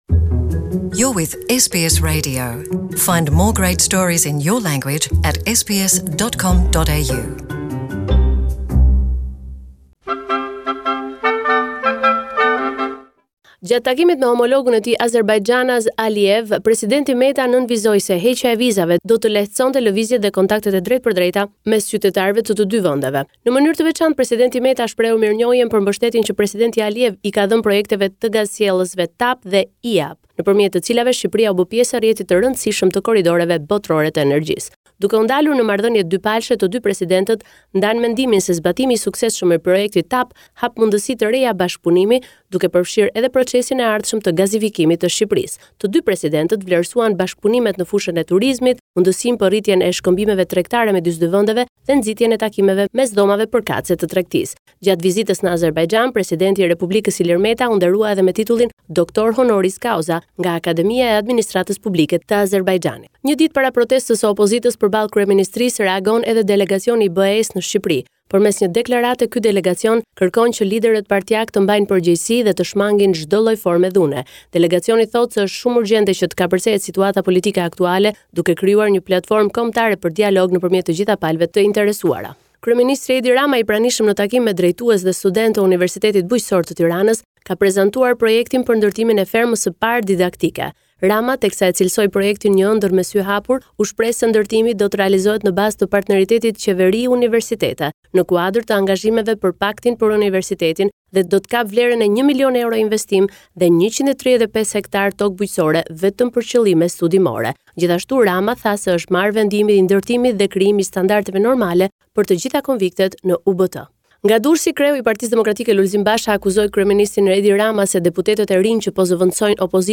This is a report summarising the latest developments in news and current affairs in Albanian